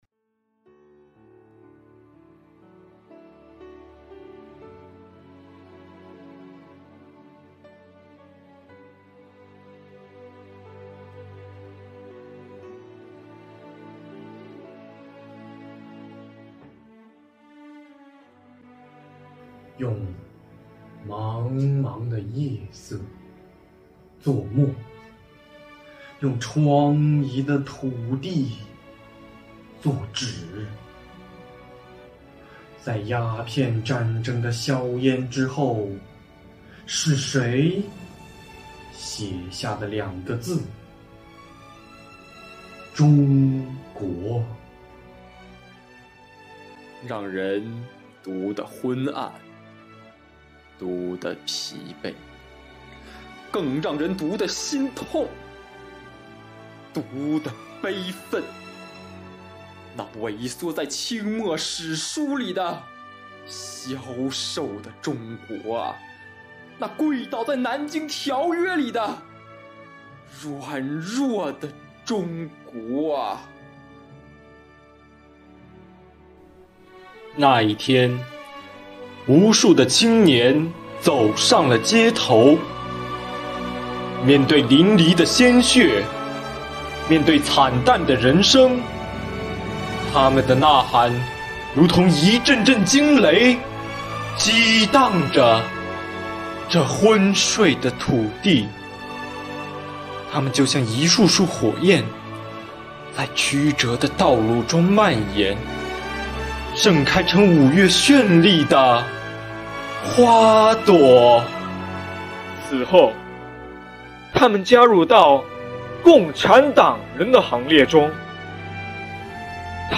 朗诵者们精神饱满，一字一句流露真情实意。
【优秀朗诵之二：《青春中国》】